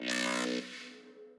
b_wobble_v100l2o3b.ogg